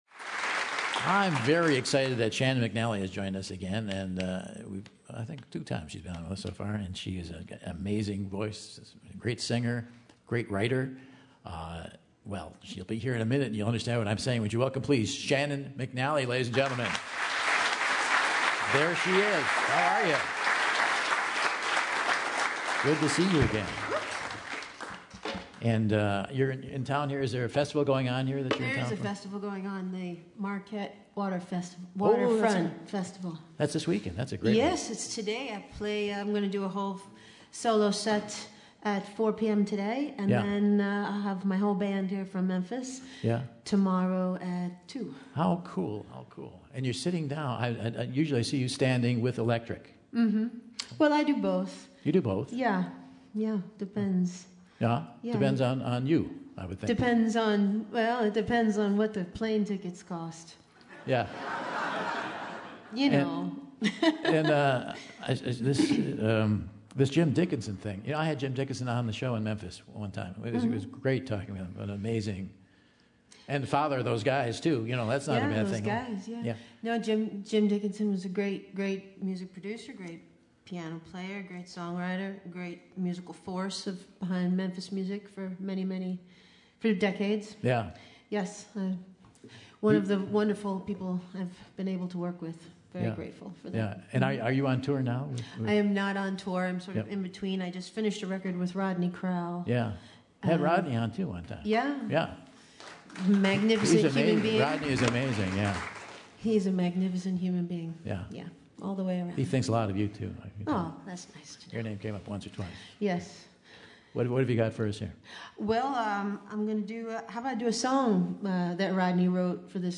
Sultry southern songstress